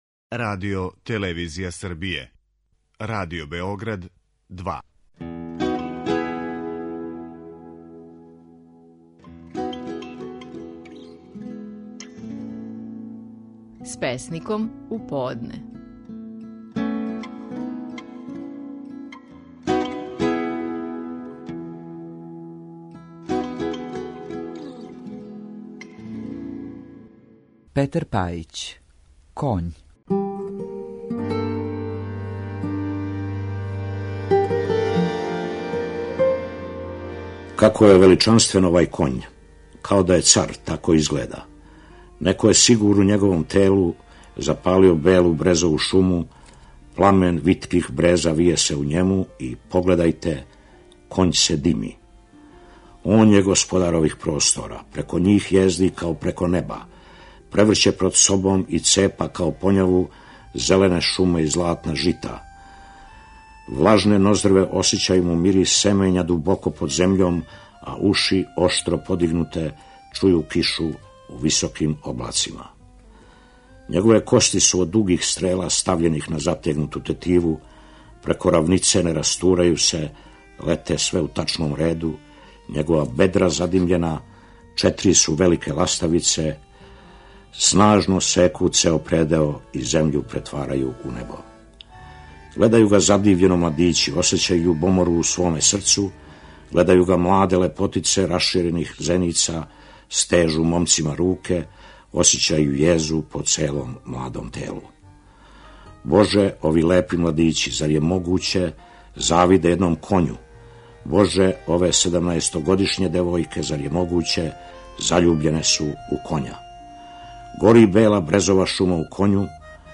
Стихови наших најпознатијих песника, у интерпретацији аутора.
Петар Пајић говори песму „Коњ".